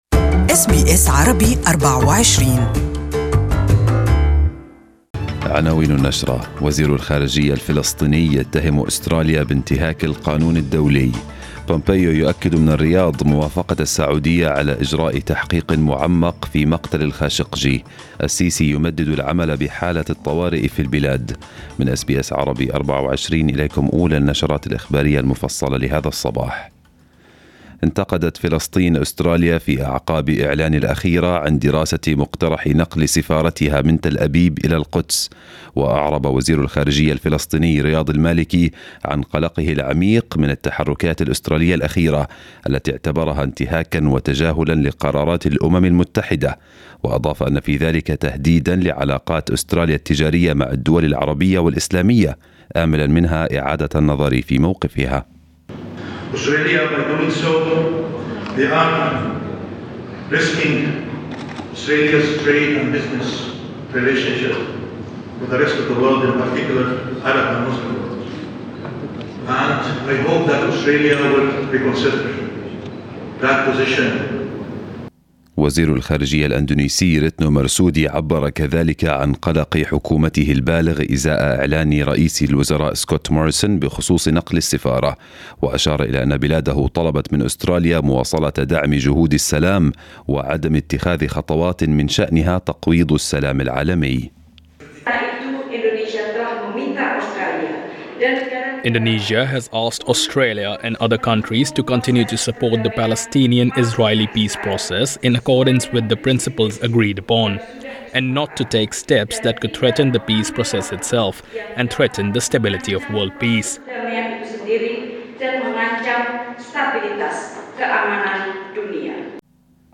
News bulletin of the morning